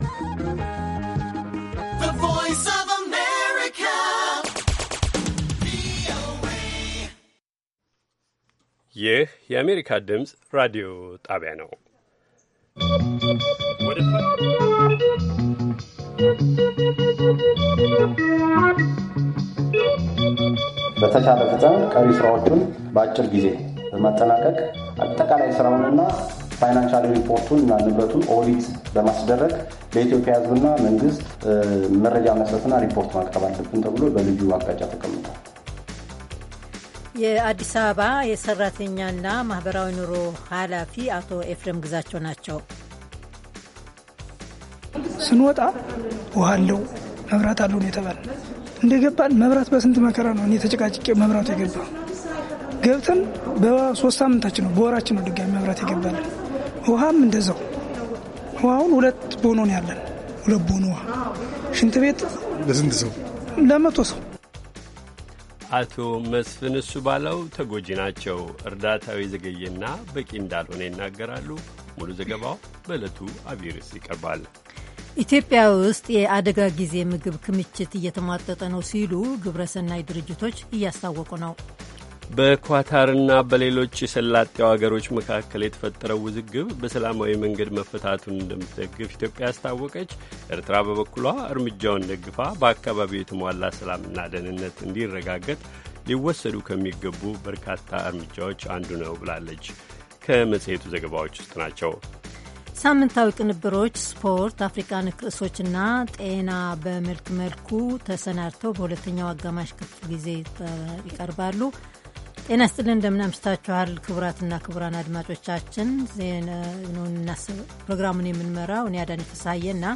ሰኞ፡-ከምሽቱ ሦስት ሰዓት የአማርኛ ዜና